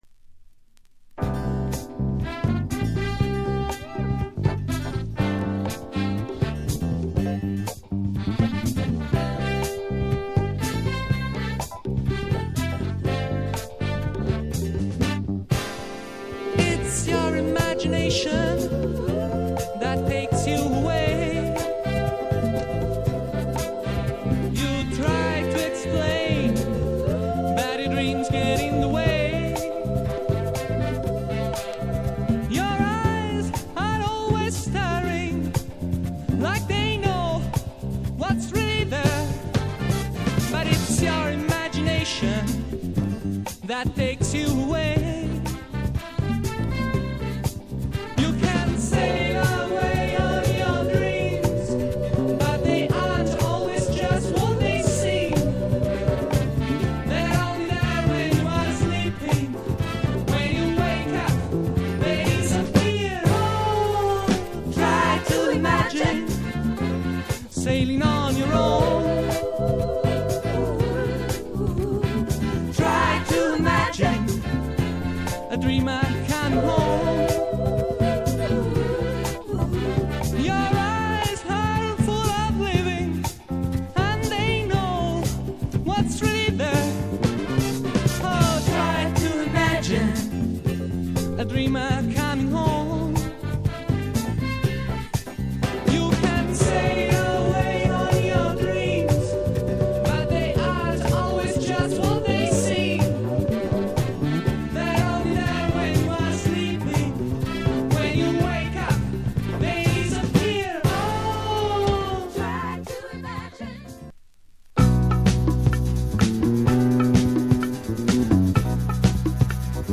人気のLA録音 AOR色の強い１枚。メロウ・フォーキーな
ミスティックなアイランド・メロウ
AOR系DJに人気絶大な１６ビート・グルーヴの
更にボトムの重心が低くフロア寄り
フェアリーなアシッド・メロウ